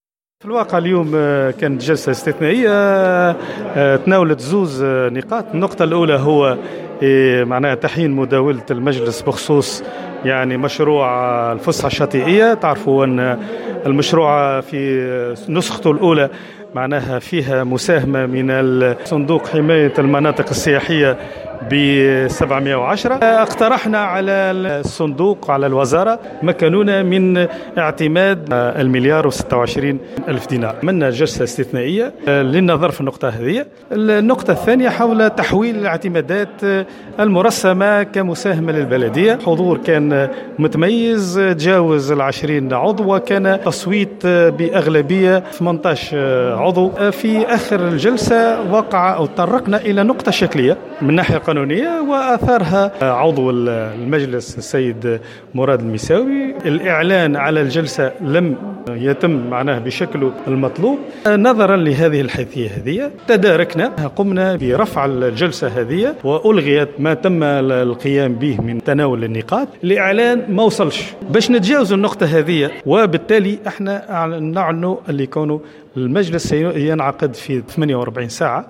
رئيس بلدية جربة حومة السوق حسين جراد يتحدث (تسجيل)